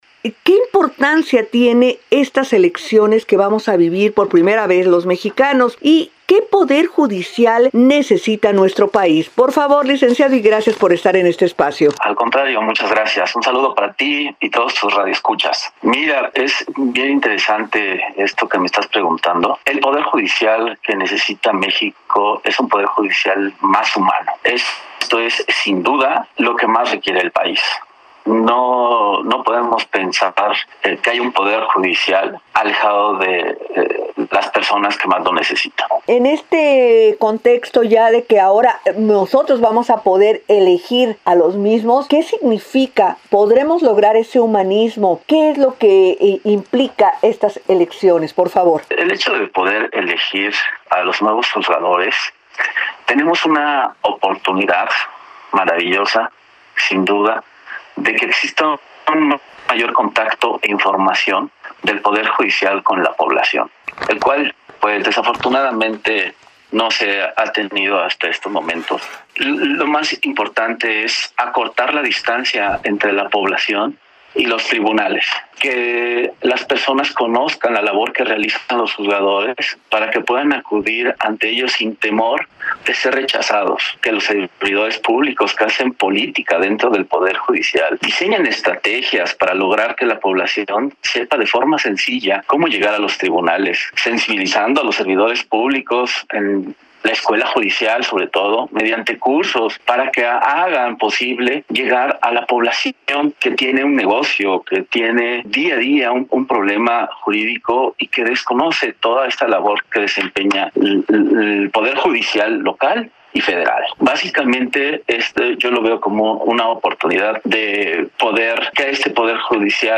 05-ENTREVISTA-ELECCIONES.mp3